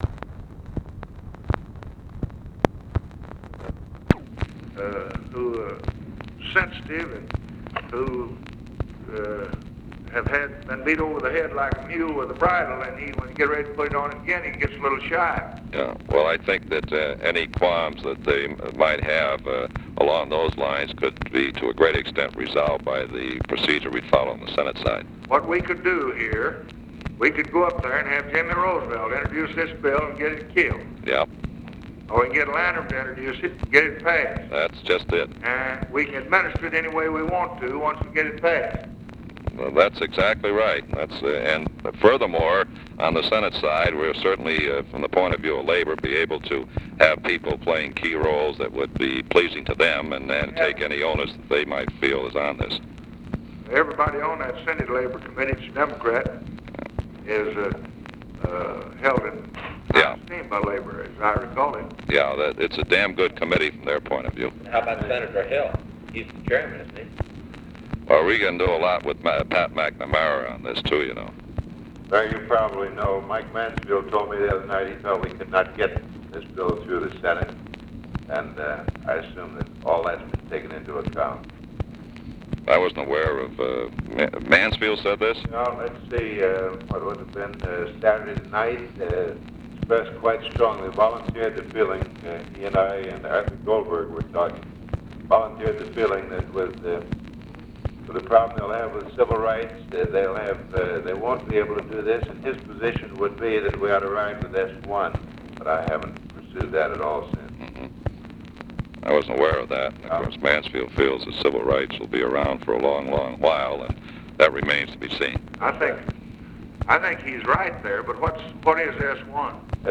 Conversation with LARRY O'BRIEN, March 11, 1964
Secret White House Tapes